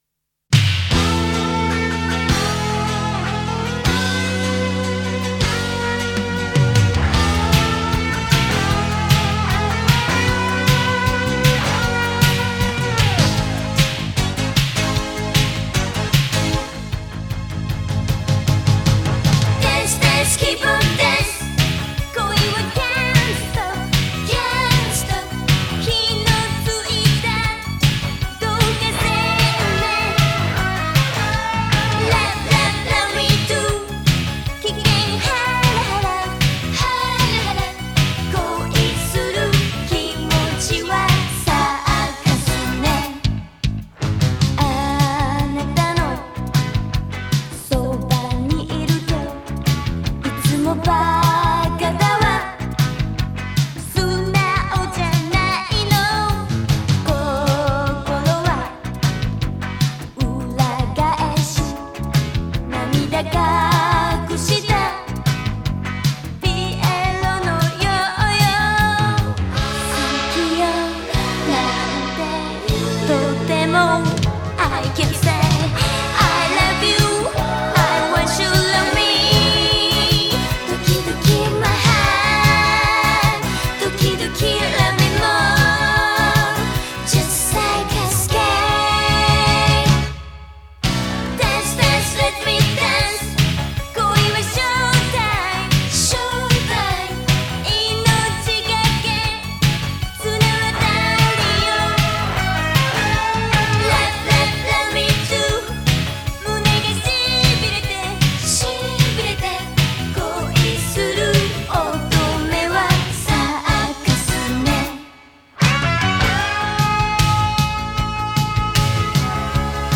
opening original japonés